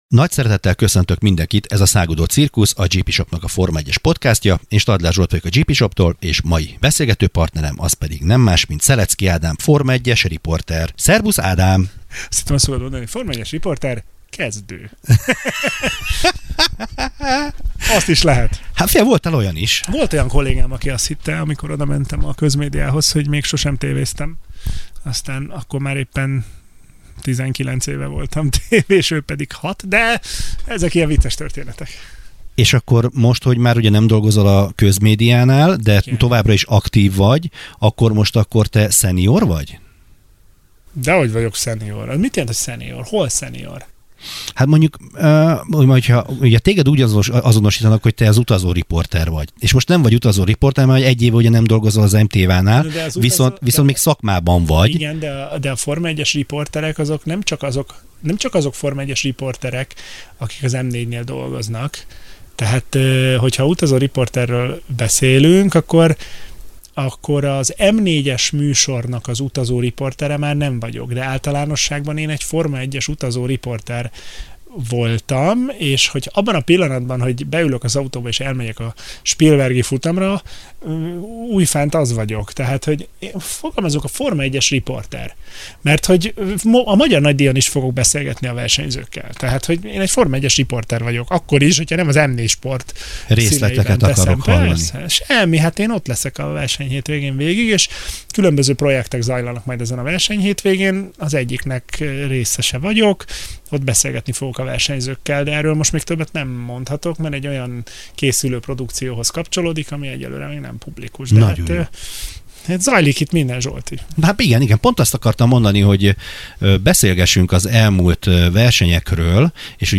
Forma-1-es talk show - szakértőktől rajongóknak. Érdekes meglátások, egyéni vélemények, máshol nem hallott sztorik.